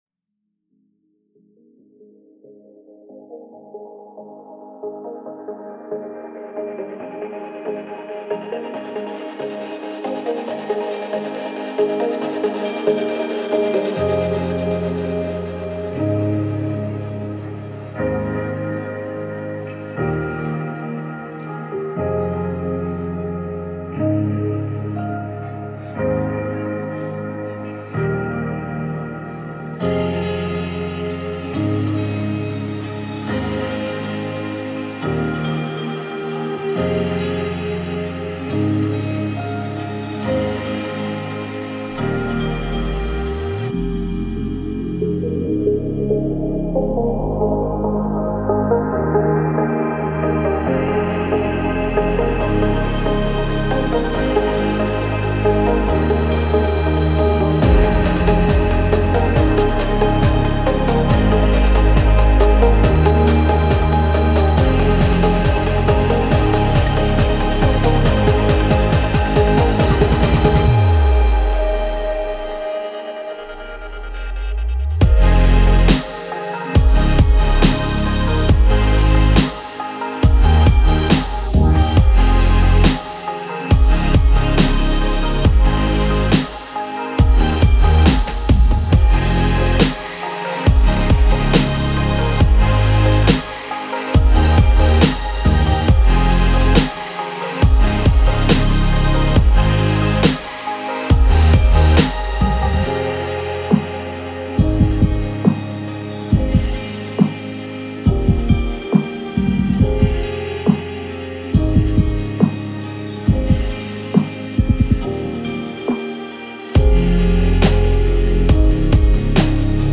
это энергичная песня в жанре поп-рок